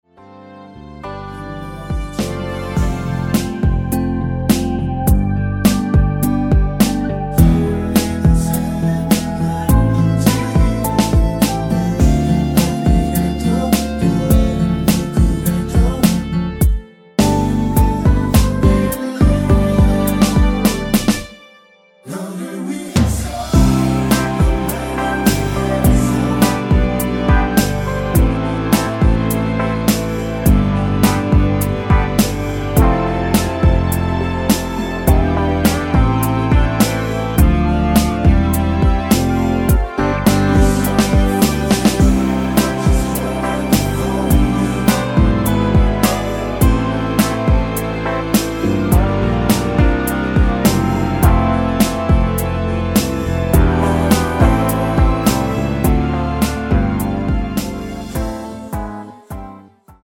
(-2) 내린코러스 포함된 MR 입니다.(미리듣기 참조)
◈ 곡명 옆 (-1)은 반음 내림, (+1)은 반음 올림 입니다.
앞부분30초, 뒷부분30초씩 편집해서 올려 드리고 있습니다.